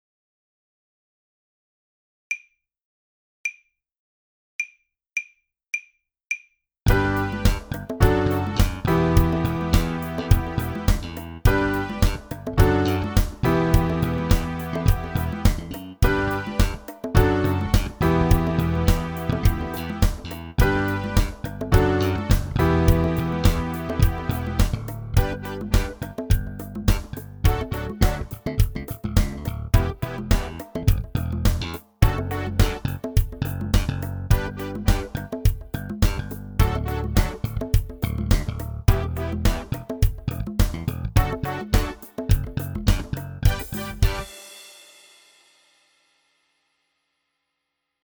sopranblockflöjt
5) STILTON G, A, B